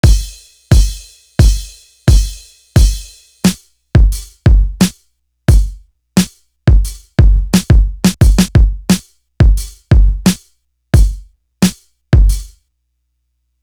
Real Mobb Do Drum.wav